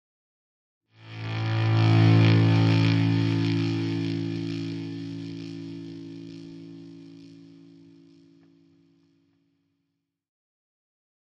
Rock Guitar - Fade Away Chord